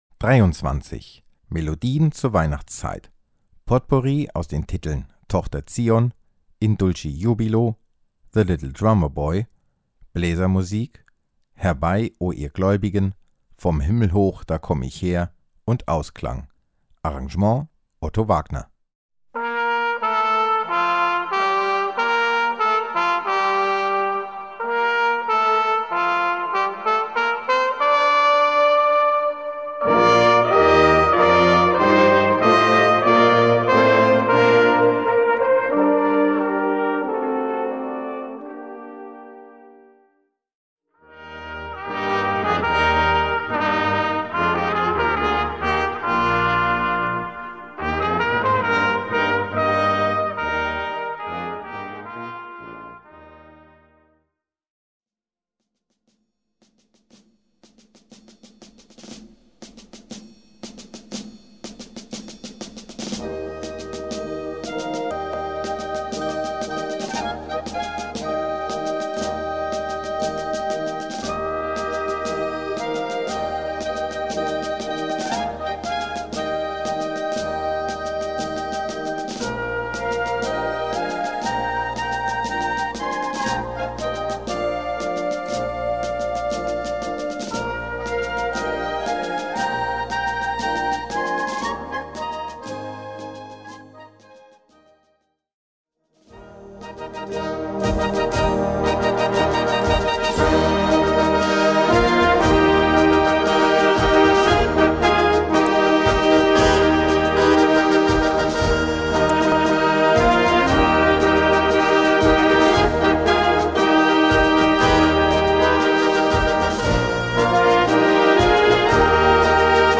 Gattung: Potpourri
Besetzung: Blasorchester